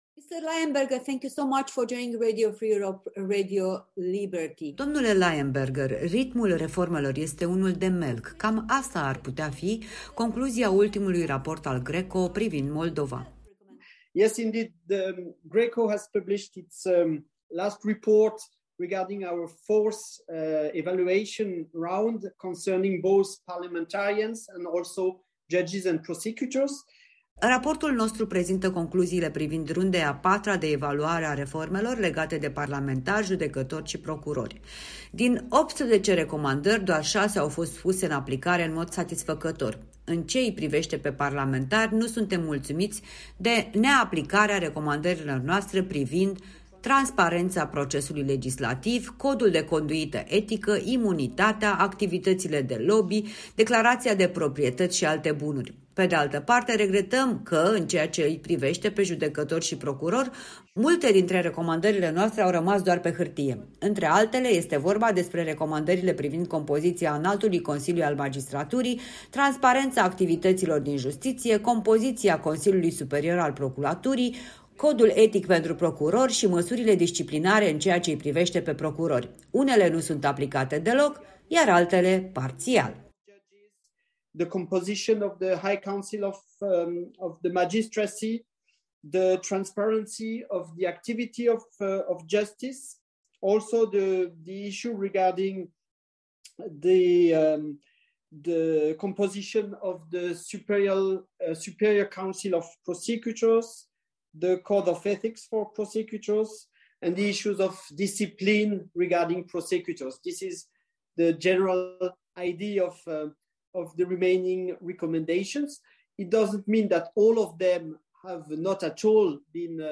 în dialog cu expertul GRECO